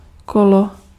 Ääntäminen
IPA: [kolo]